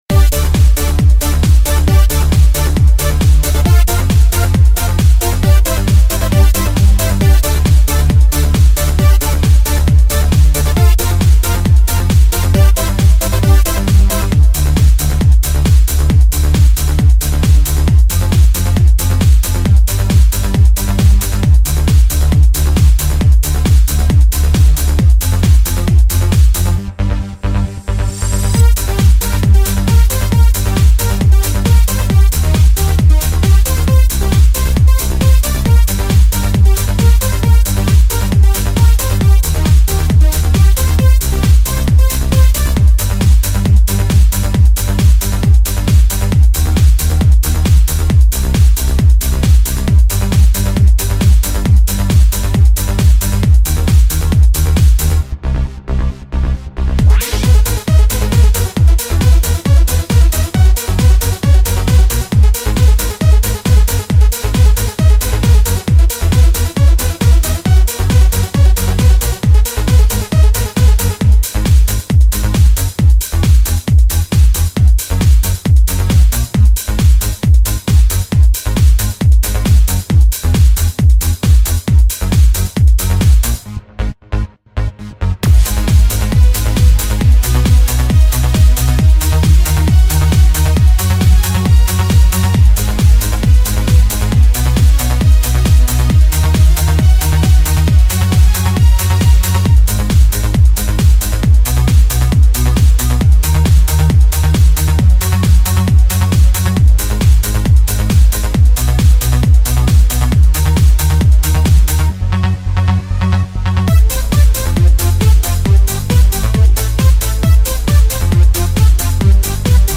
90_х_Eurodance_Megamix
90_h_Eurodance_Megamix.mp3